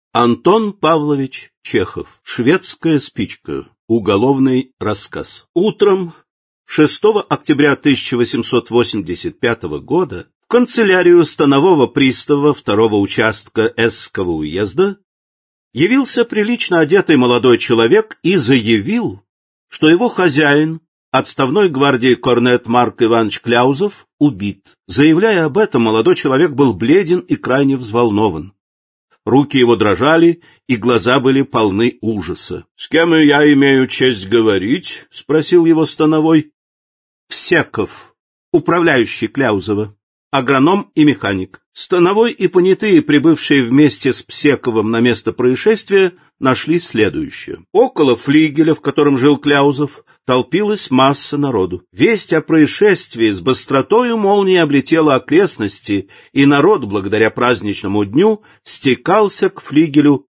Aудиокнига Классика русского детективного рассказа № 1 Автор Сборник Читает аудиокнигу Сергей Чонишвили.